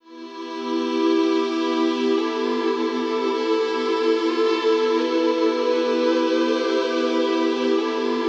WEEPING 1 -R.wav